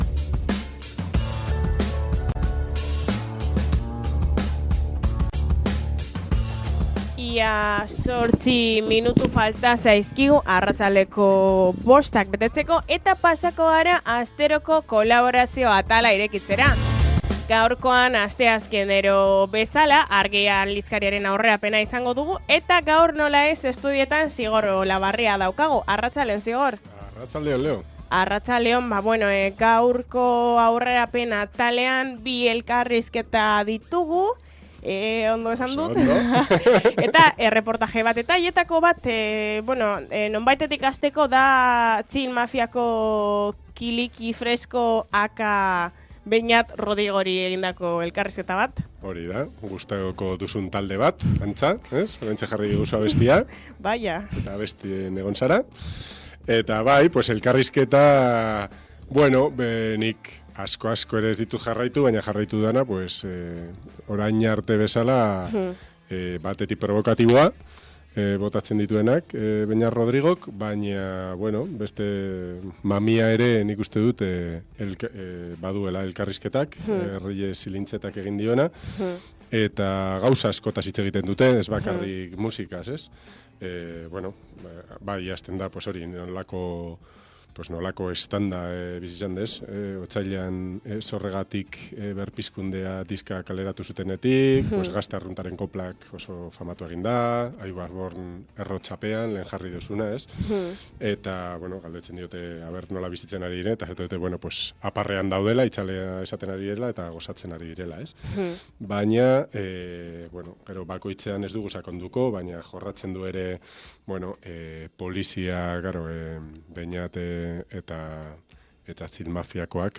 Kanadako ume autoktonoentzako barnetegiak erreportajea eta Ekida elkarrizketa